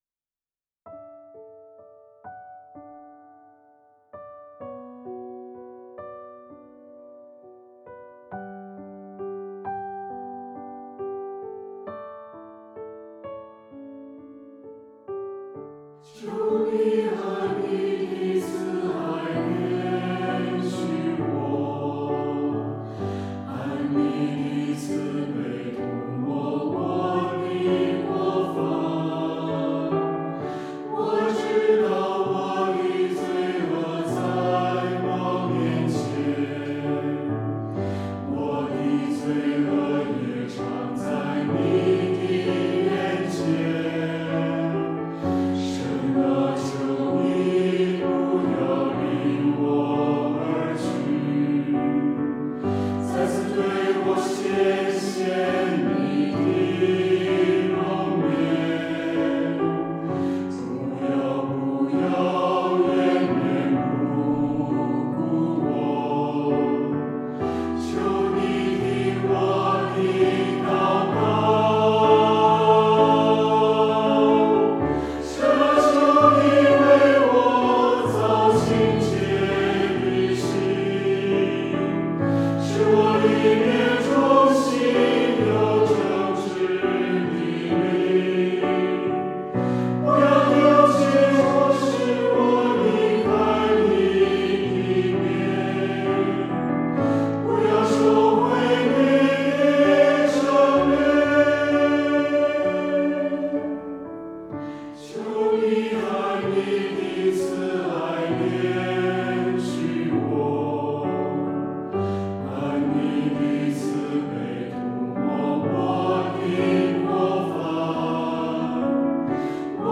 唱诗：给我清洁的心（新381）